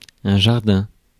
Ääntäminen
Ääntäminen France: IPA: [ʒaʁ.dɛ̃] Haettu sana löytyi näillä lähdekielillä: ranska Käännös Substantiivit 1. aed Muut/tuntemattomat 2. õu 3. juurviljaaed Suku: m .